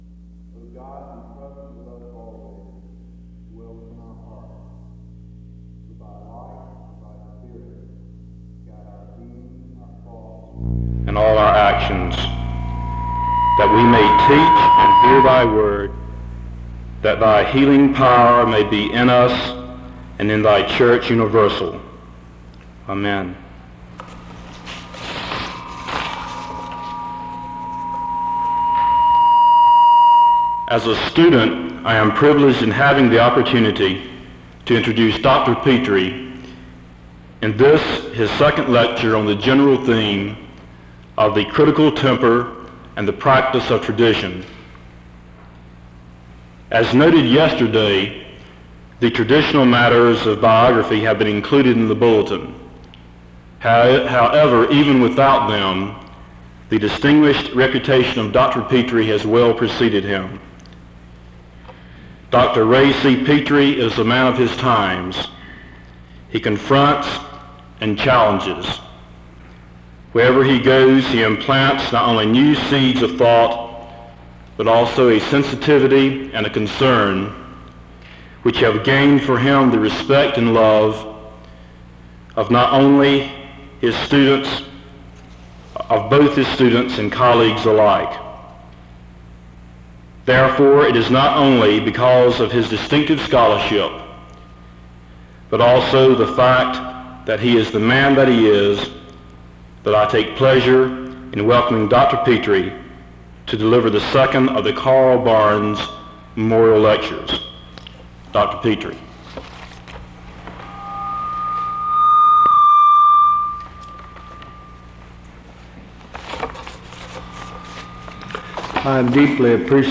Home SEBTS Carver-Barnes Lecture...